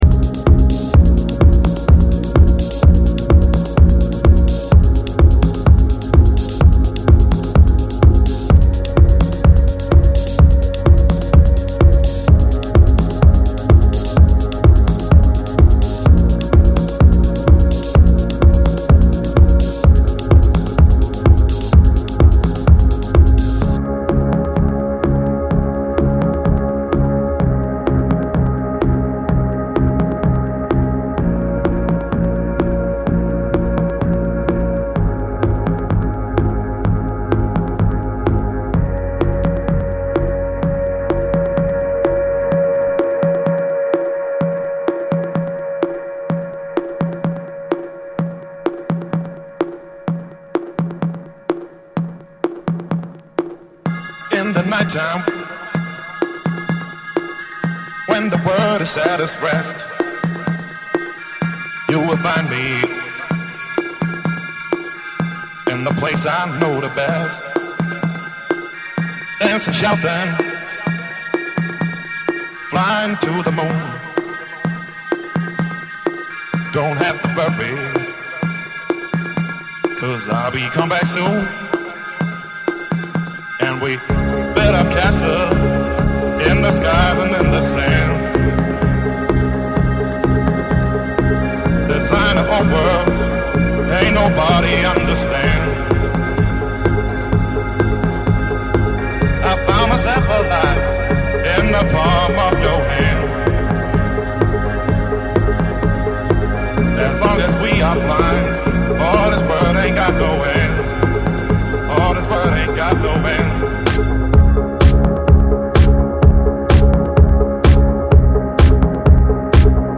Styl: House, Techno